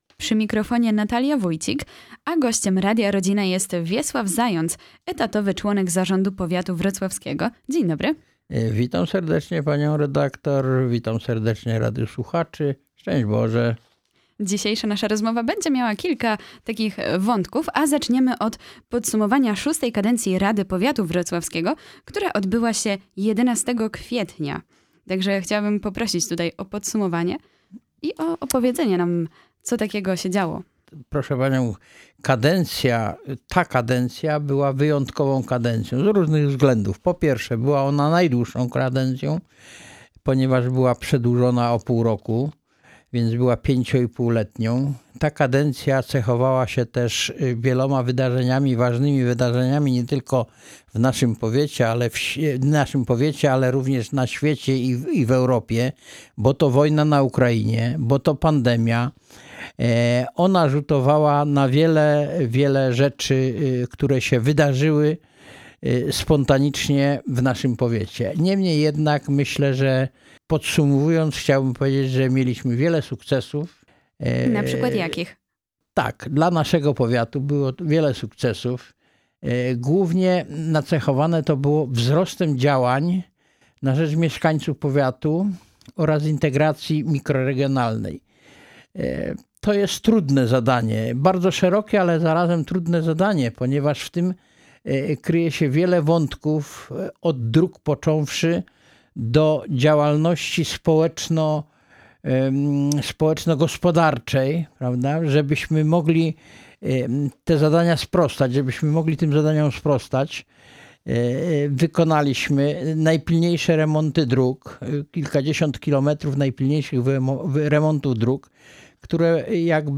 W studiu Radia Rodzina gościliśmy dziś Wiesława Zająca, który podsumował VI kadencję Rady Powiatu Wrocławskiego, opowiedział o perspektywach na kolejną, VII kadencję.